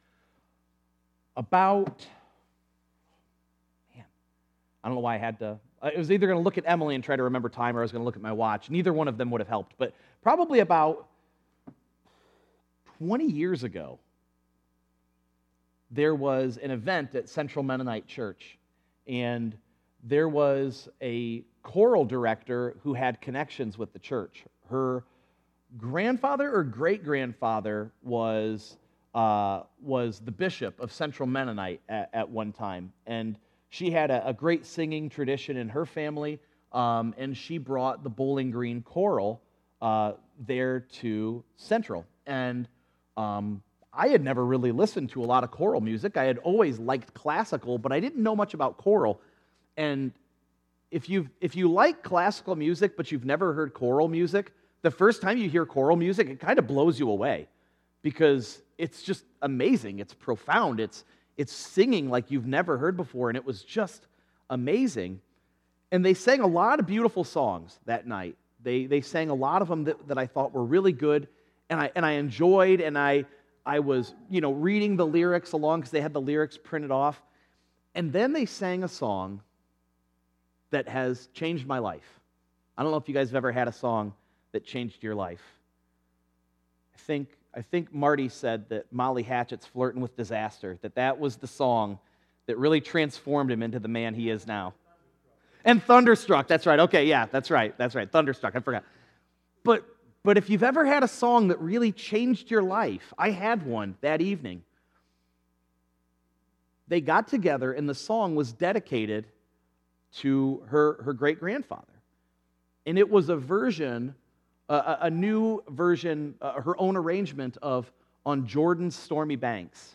Sermons - First Brethren Church- Bryan Ohio